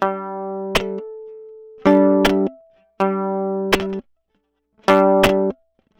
I play on the violin a certain combination of notes and chops (chops are simply hitting or chopping the bow against the strings without making a clear specific note) - to match the rhythm that I want; namely just a simple KICK and SNARE type sound.
But in essence - I play one thing or note to trigger or represent the KICK and a different thing or notes /chop sound to trigger or represent the SNARE.